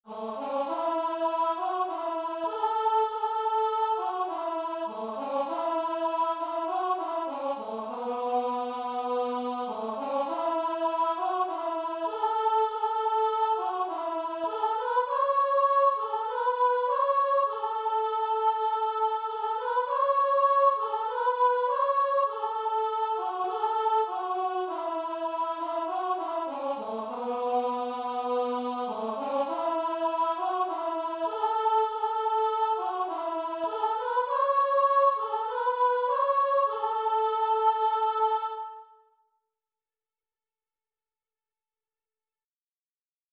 Christian
4/4 (View more 4/4 Music)
Guitar and Vocal  (View more Easy Guitar and Vocal Music)